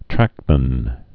(trăkmən)